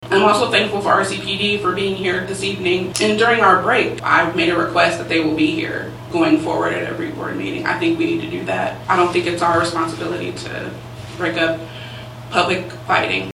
Tensions rose between the public during Wednesday’s USD 383 Manhattan-Ogden school board meeting.
Coleman says she was thankful members of the public were able to come back and join the meeting after the recess.